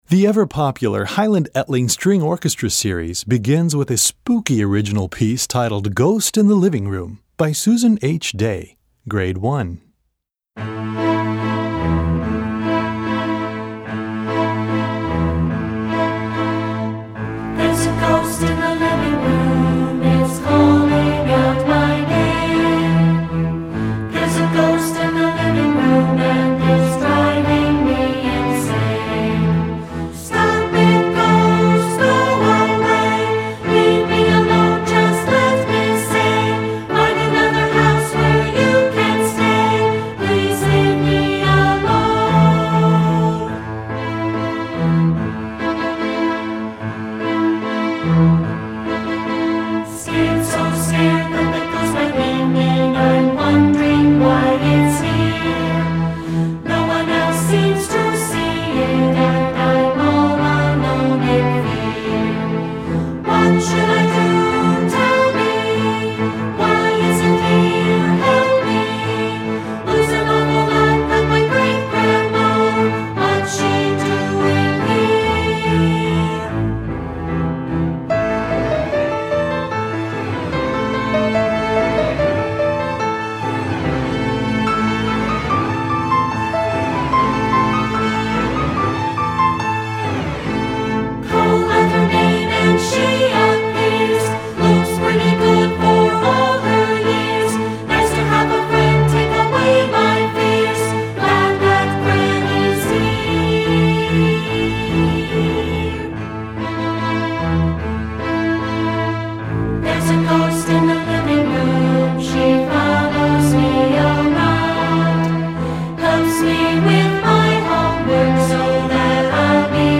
Gattung: Streichorchester
Besetzung: Streichorchester
The piano part is integral to the piece.